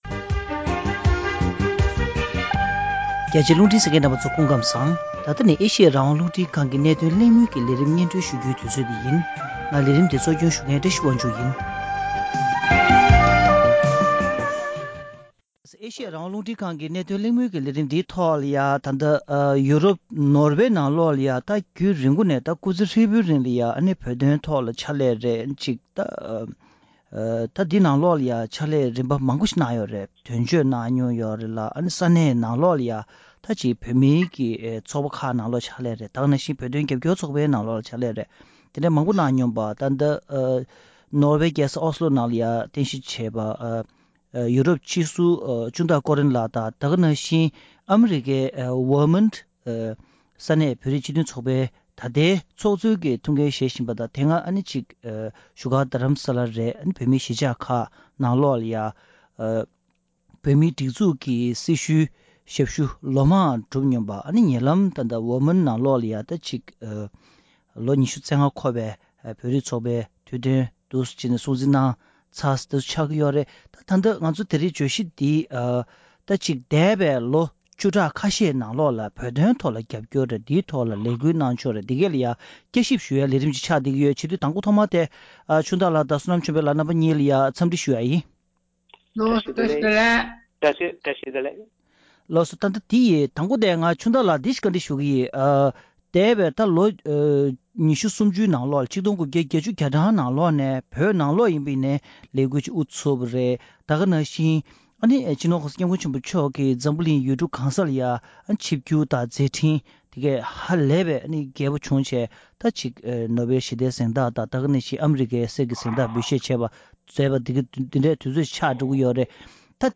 རྒྱལ་སྤྱིའི་ངོས་ནས་བོད་དོན་རྒྱབ་སྐྱོར་གྱི་གནས་བབས་ཐད་གླེང་མོལ།